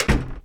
wooden_door / close.ogg